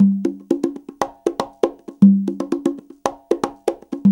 CONGA BEAT39.wav